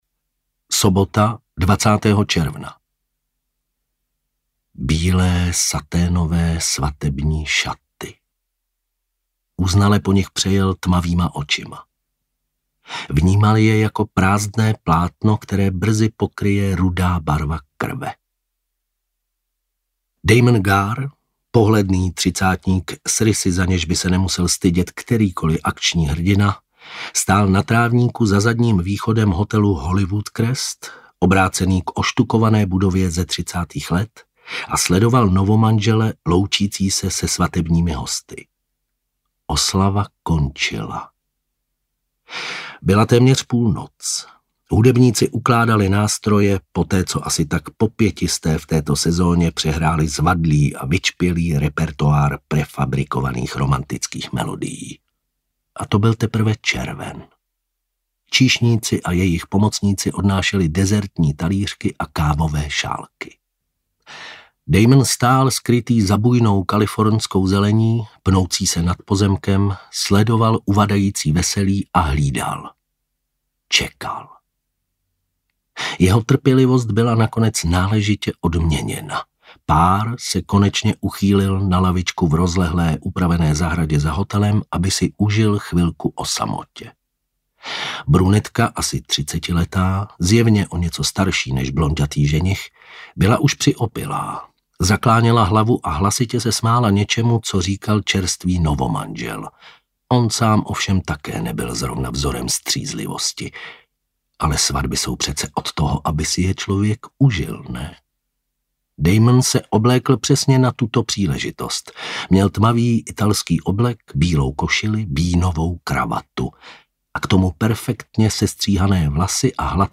Audiokniha: Umelec
Nahraté v štúdiu: Chevaliere